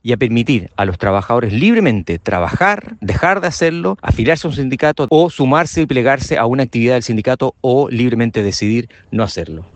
Desde la comisión de Educación, el diputado republicano Stephan Schubert hizo un llamado a cesar las amenazas y a respetar las normas laborales.